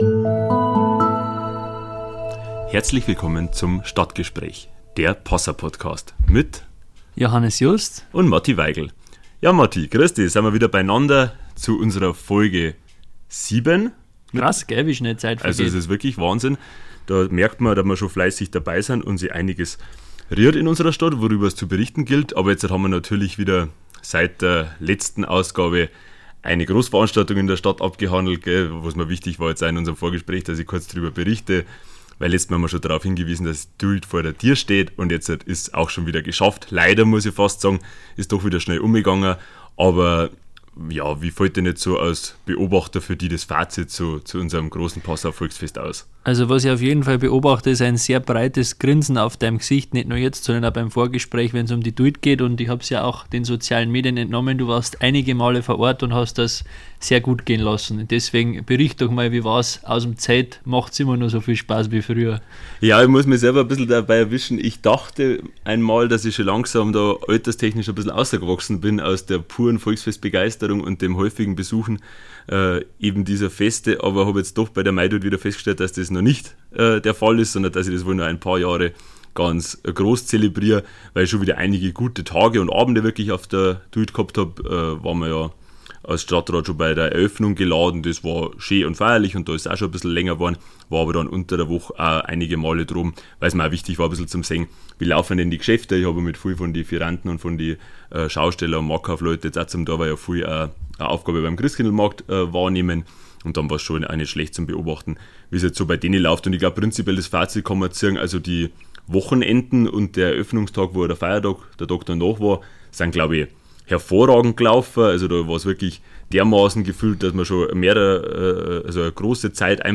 Die beiden jüngsten Mitglieder des Passauer Stadtrats, Matthias Weigl (Grüne) und Johannes Just (SPD), sprechen über aktuelle Themen der Lokalpolitik und beziehen klar Stellung.